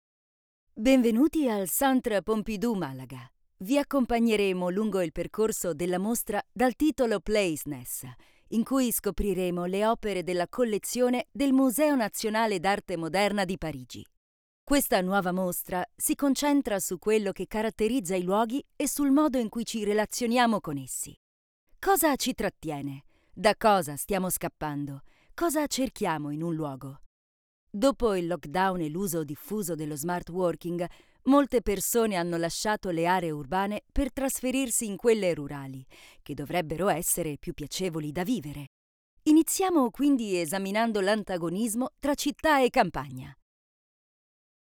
Audio Guides
Her voice is sincere, soft, warm,versatile, friendly, natural, smooth.
ContraltoMezzo-Soprano
SincereSoftWarmVersatileFriendlyNaturalSmooth